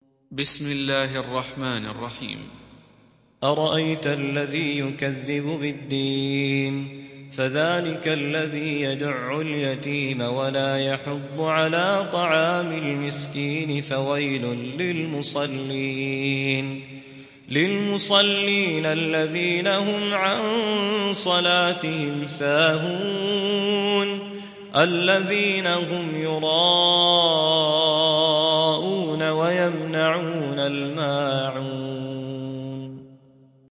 حفص عن عاصم